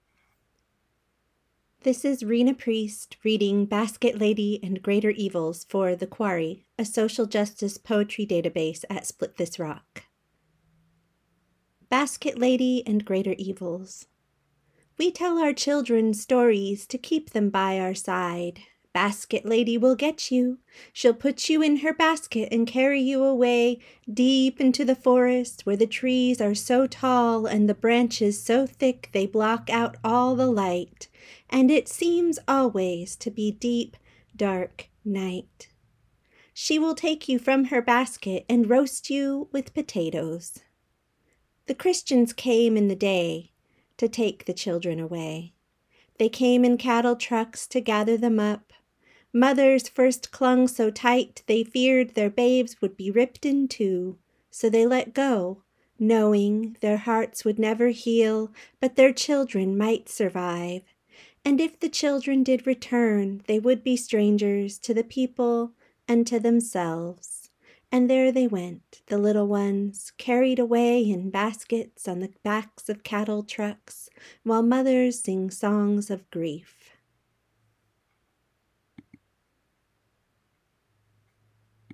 Listen as Rena Priest reads Basket Lady and Greater Evils.